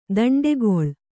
♪ daṇḍegoḷ